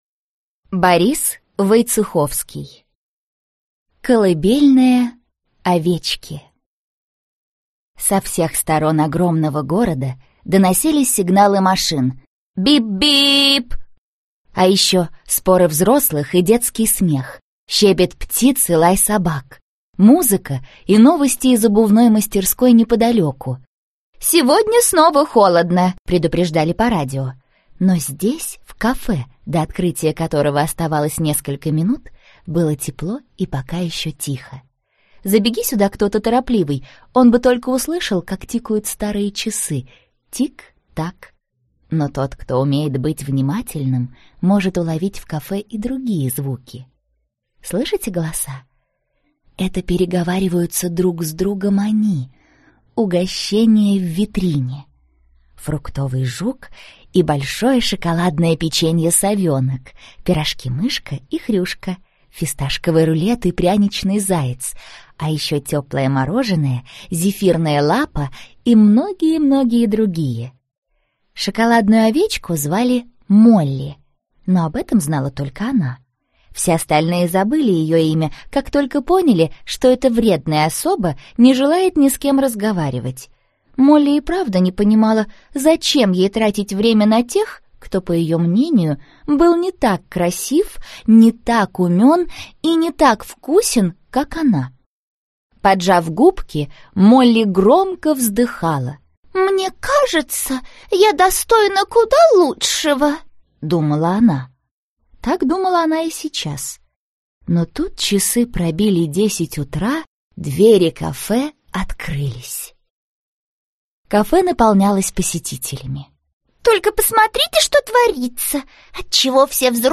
Аудиокнига Колыбельная овечки | Библиотека аудиокниг